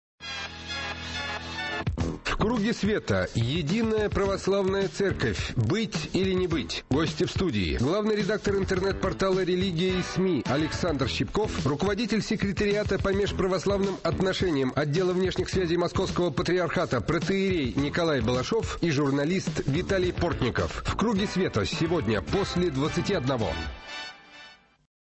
Аудио: анонс –